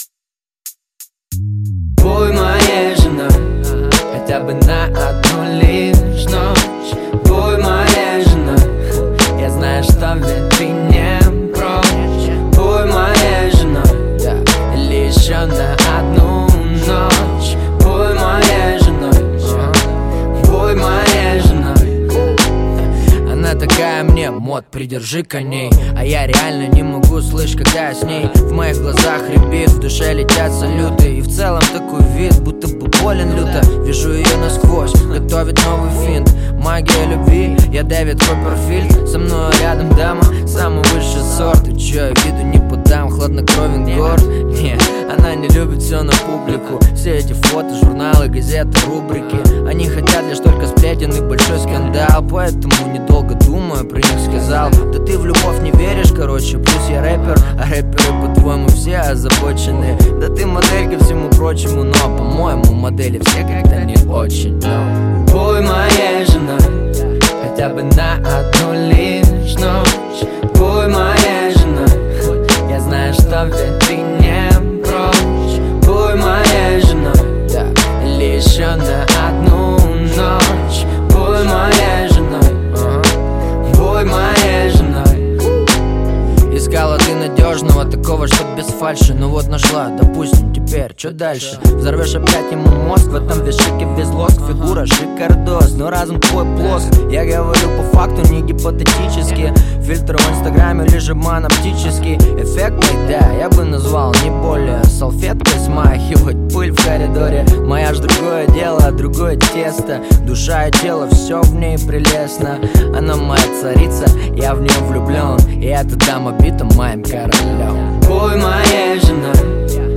Жанр: Русский рэп / Хип-хоп